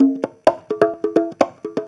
Conga Loop 128 BPM (9).wav